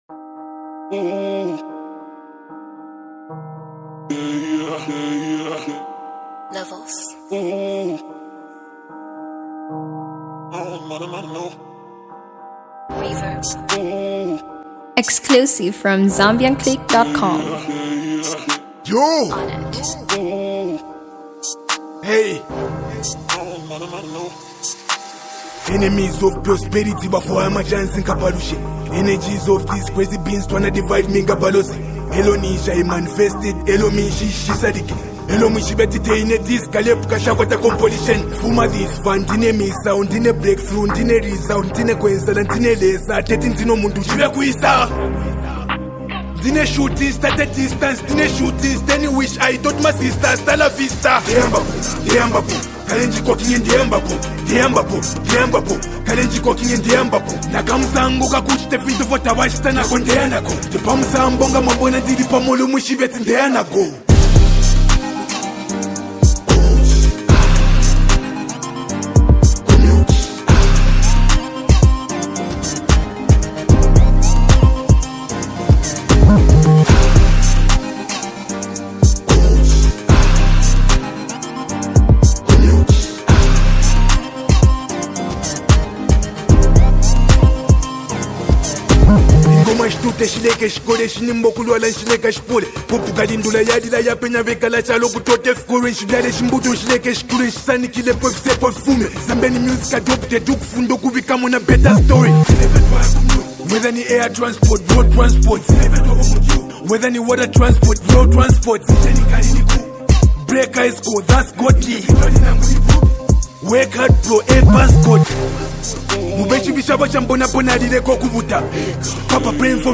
Zambian heavyweight rapper
powerful hip-hop song